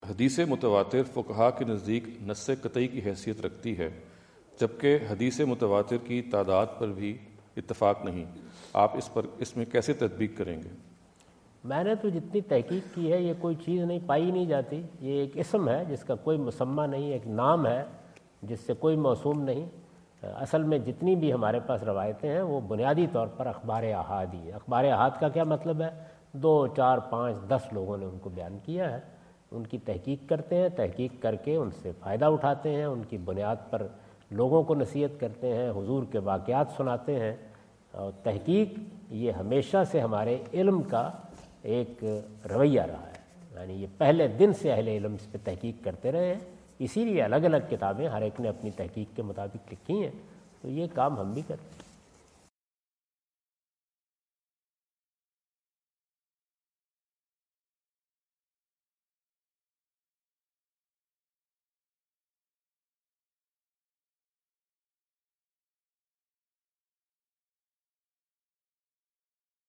Category: English Subtitled / Questions_Answers /
Javed Ahmad Ghamidi answer the question about "Hadith Mutawatir" asked at The University of Houston, Houston Texas on November 05,2017.
جاوید احمد غامدی اپنے دورہ امریکہ 2017 کے دوران ہیوسٹن ٹیکساس میں "حدیثِ متواتر" سے متعلق ایک سوال کا جواب دے رہے ہیں۔